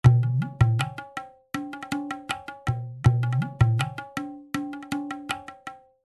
ネイティブアメリカンサウンド
インドの太鼓の音 ダウンロード90.22 kB96kB9